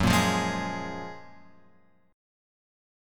F# Major 9th